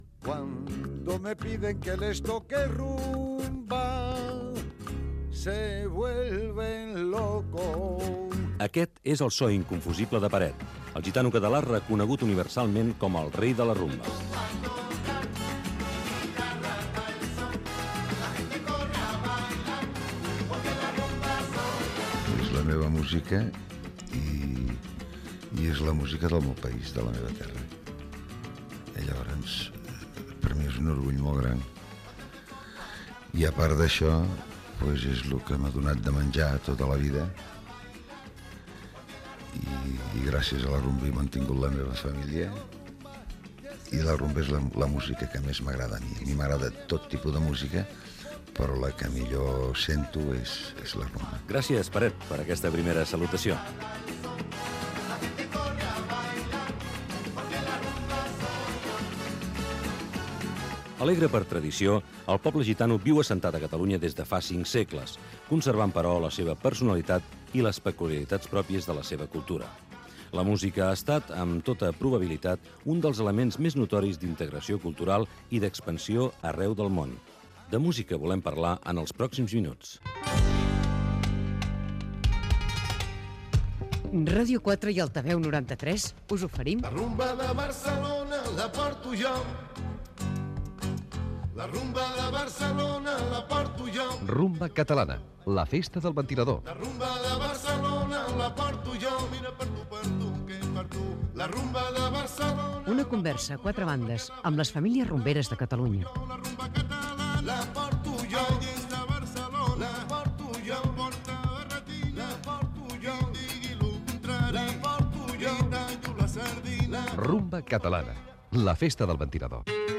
Salutació del cantant Peret (Pere Pubill) i careta del programa. Inici del capítol 1: el desembarcament.
Musical